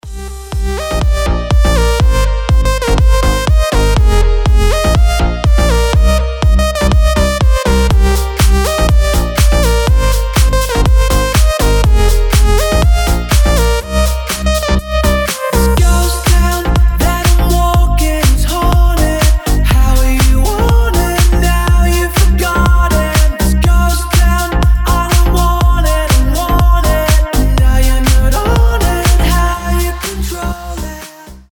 громкие
Electronic
EDM
progressive house
slap house
Клубный звук на телефон